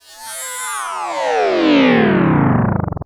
SCIFI_Sweep_05_mono.wav